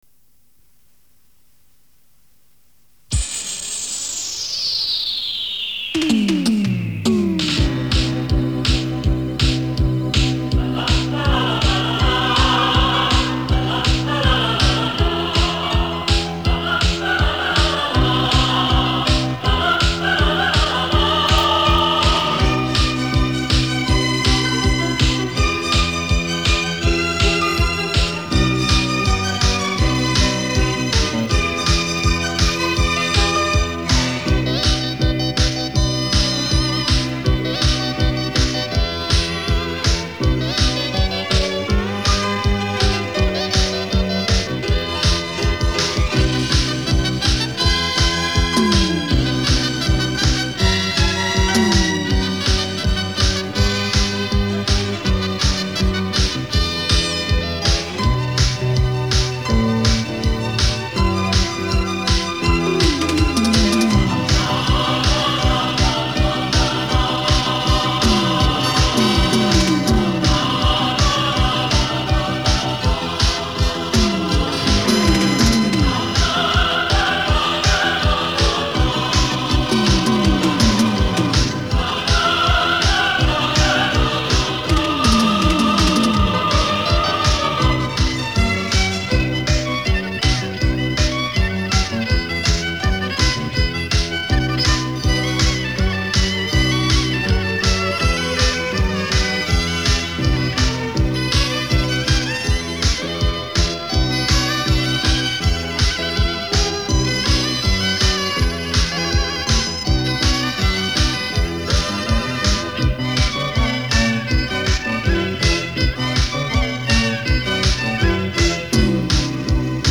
迪斯科.探戈.伦巴.三步.喳喳
【专辑类别】电子音乐
电子音乐舞曲的节奏明显
音质好，像用母带制作，谢谢
基本都是广东音乐改编的电子音乐舞曲，很有创意
这休电子琴音乐真是经典！